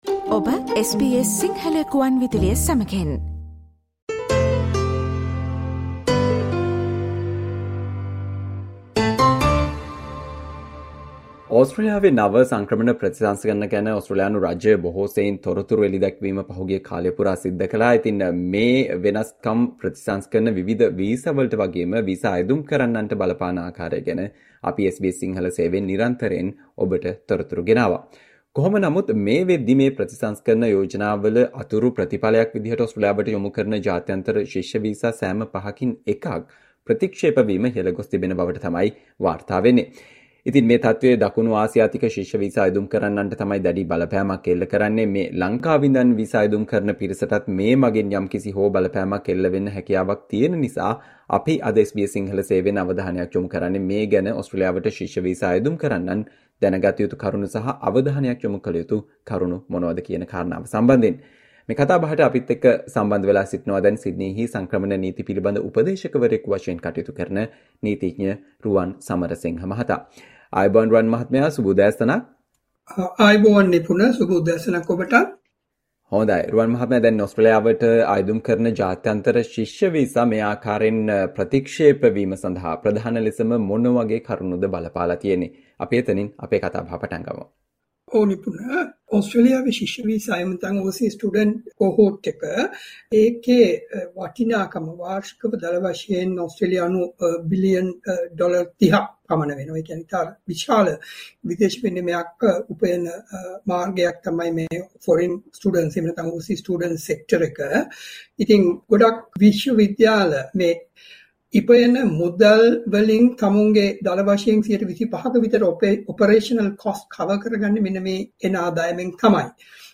SBS Sinhala discussion on Why international student visa applications to Australia are facing a sharp uptick in rejection rate